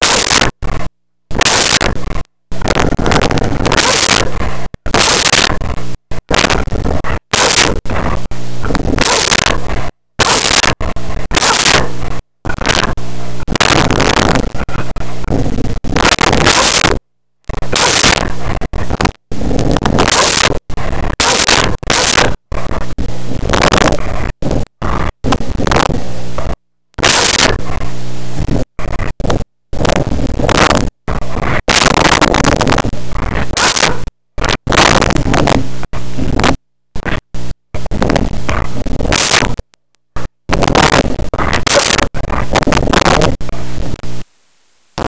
Dog Medium Barking 01
Dog Medium Barking 01.wav